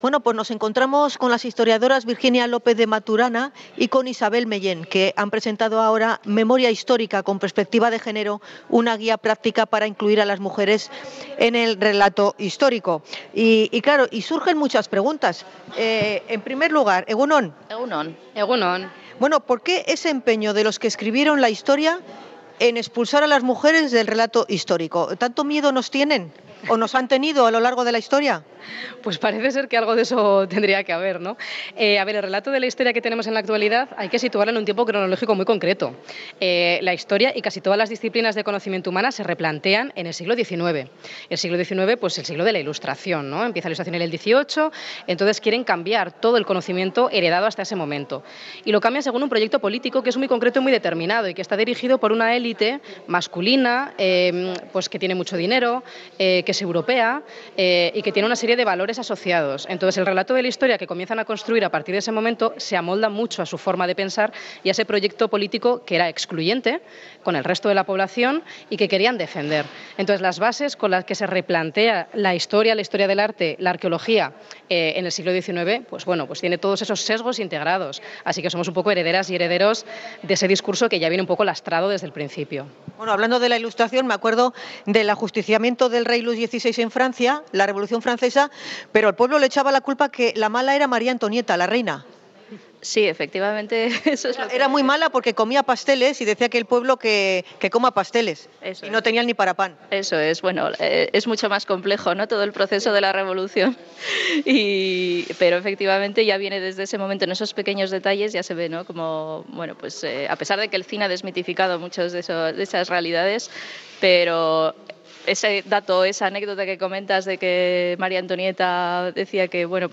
Podcast Sociedad
INT.-MEMORIA-HISTORICA-EMAKUNDE.mp3